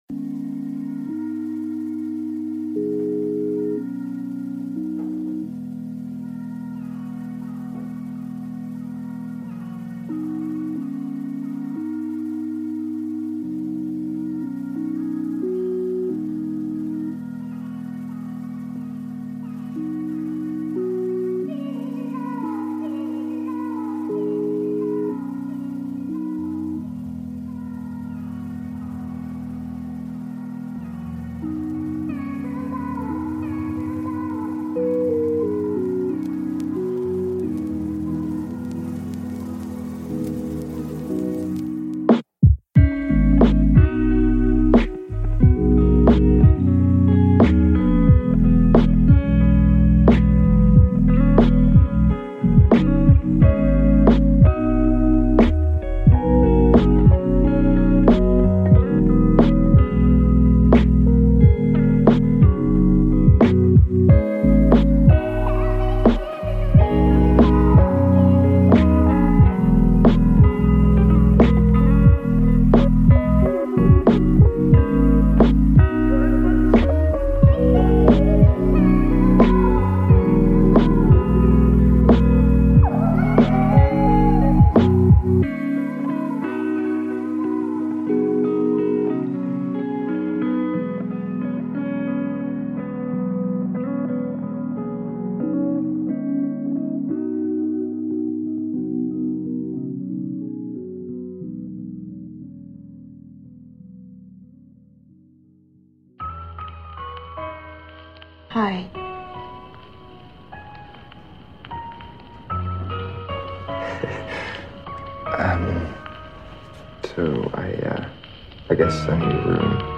Aucun bruit parasite, aucune coupure soudaine.